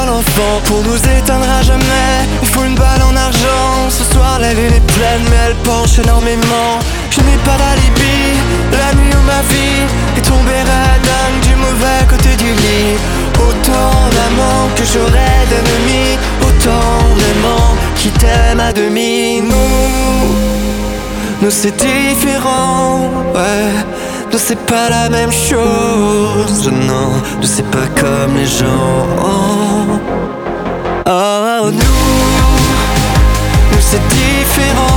Скачать припев
French Pop
2025-06-27 Жанр: Поп музыка Длительность